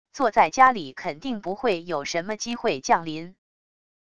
坐在家里肯定不会有什么机会降临wav音频生成系统WAV Audio Player